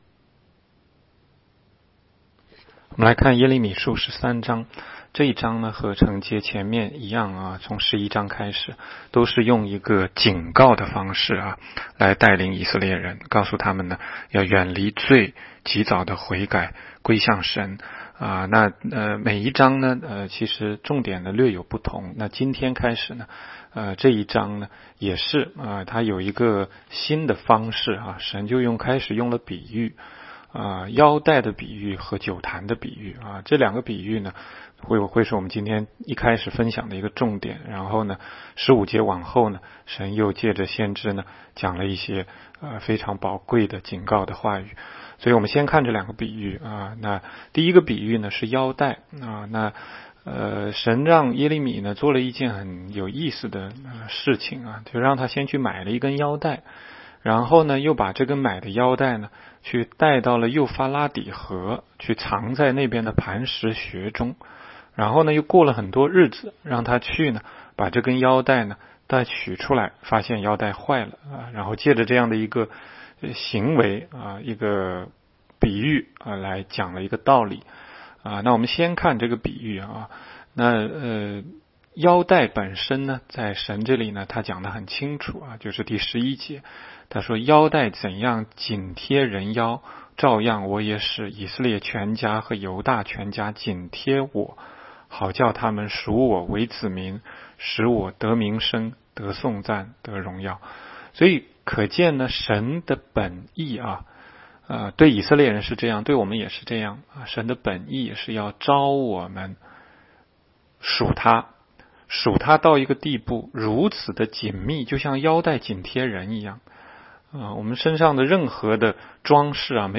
16街讲道录音 - 每日读经 -《耶利米书》13章